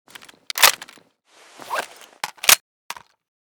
mp5_reload.ogg